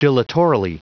Prononciation du mot dilatorily en anglais (fichier audio)
Prononciation du mot : dilatorily